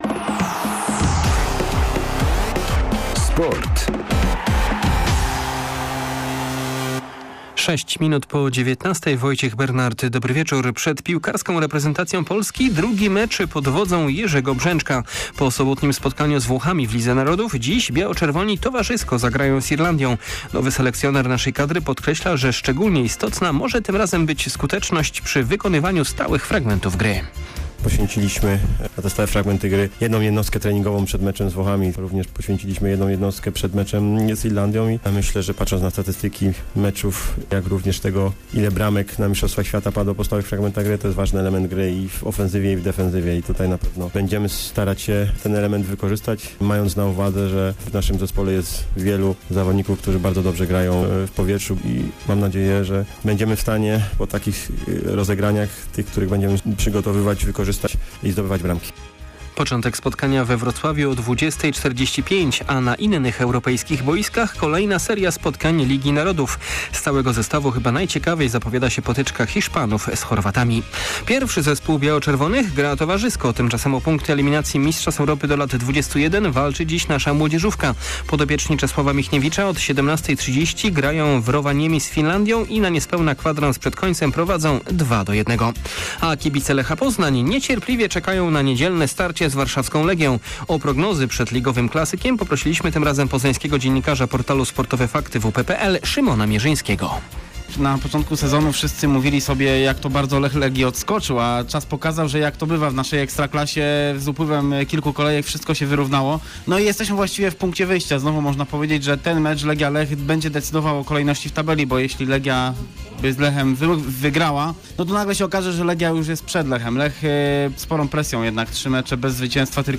11.09. SERWIS SPORTOWY GODZ. 19:05